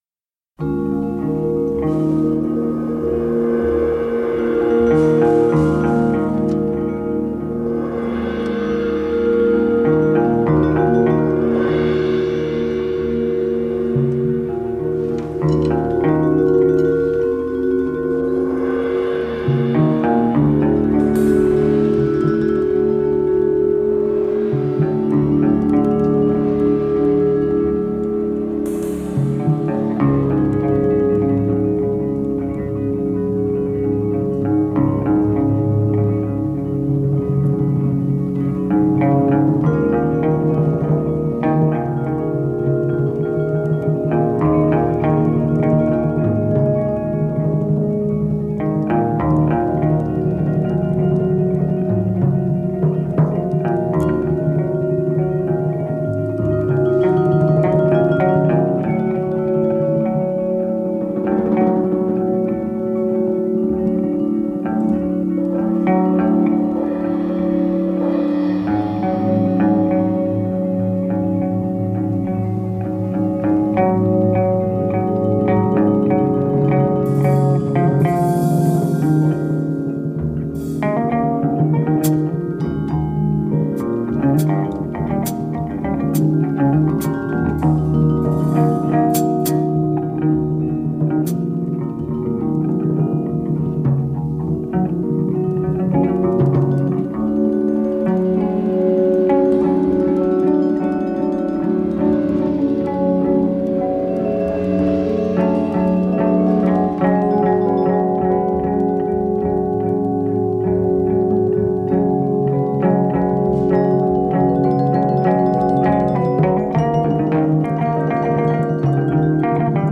in concert from Hamburg - recorded by North German Radio